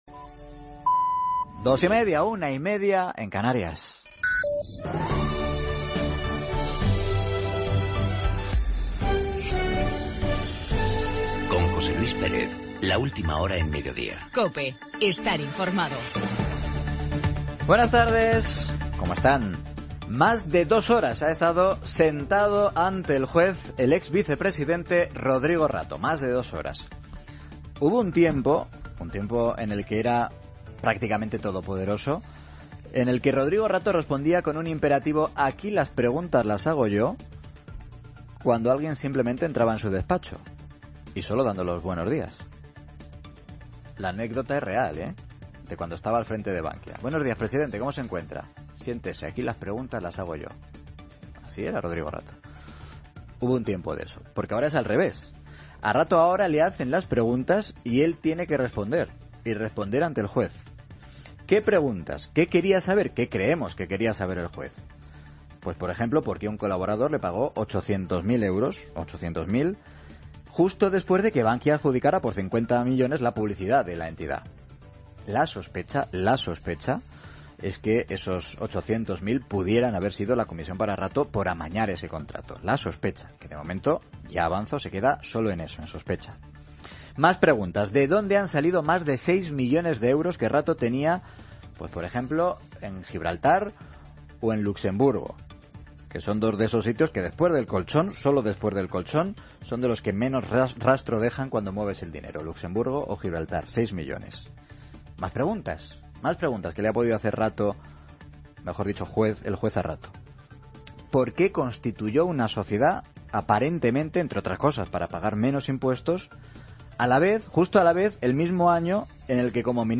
Todas las noticias del lunes